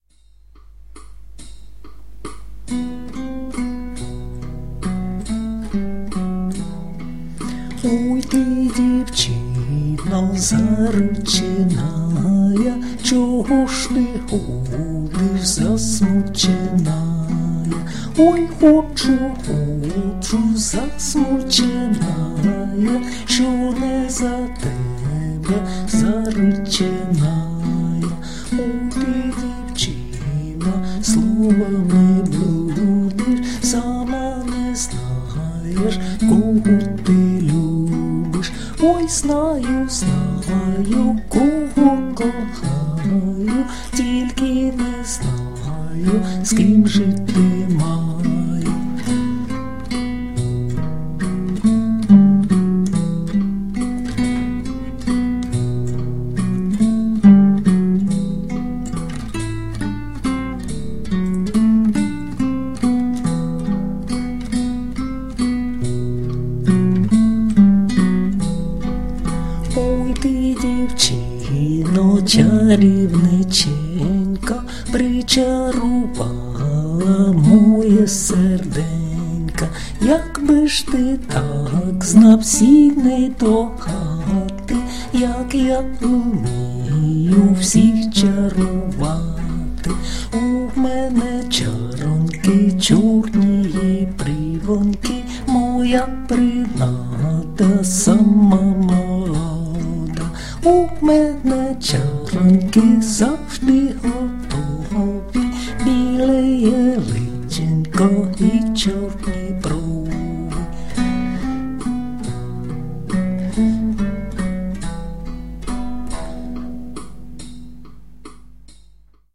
../icons/oyzagaem.jpg   Українська народна пісня